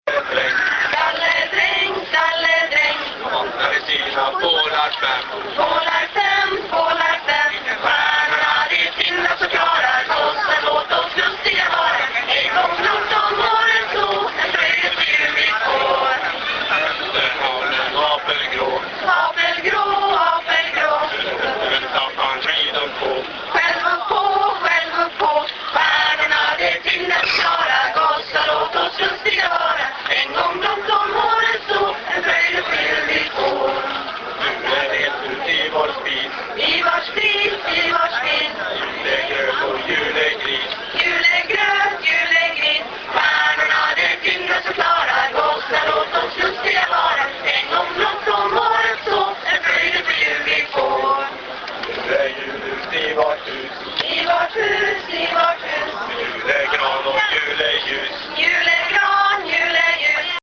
каждый год в этот день эти шведские юноши и девушки привыкли петь гимны во славу Святой Люсии, и вот, оказавшись в этот день на берегах священной индийской Ганги, они решили продолжить эту традицию